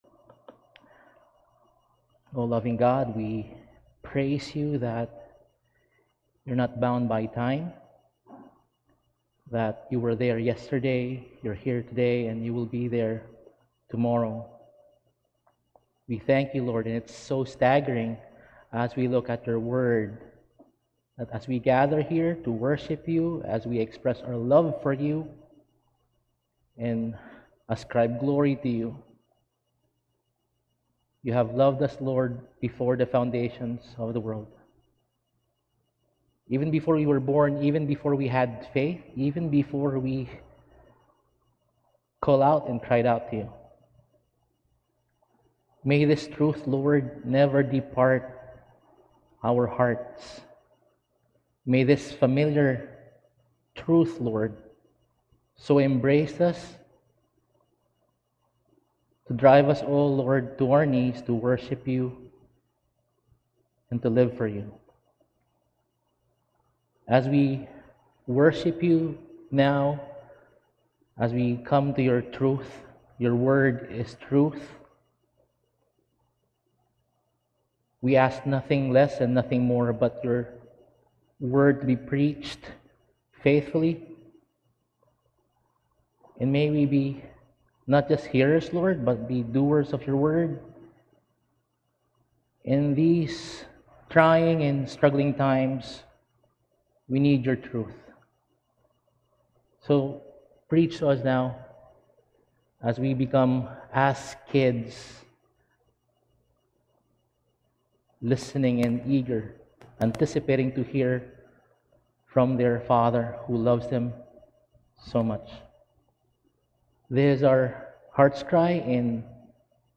Service: Sunday Sermon